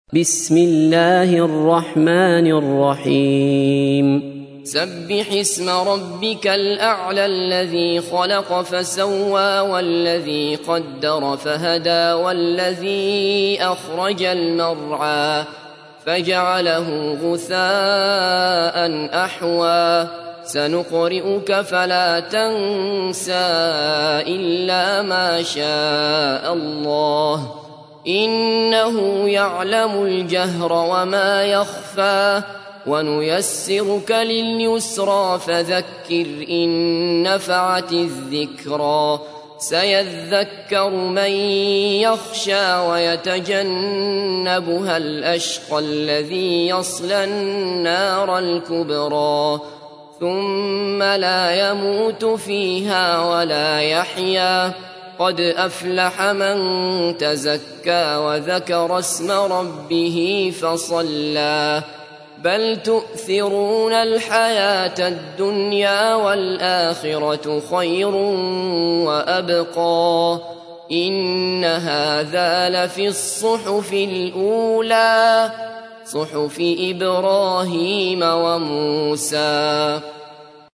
تحميل : 87. سورة الأعلى / القارئ عبد الله بصفر / القرآن الكريم / موقع يا حسين